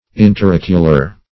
Interocular \In`ter*oc"u*lar\, a.